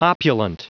Prononciation du mot opulent en anglais (fichier audio)
Prononciation du mot : opulent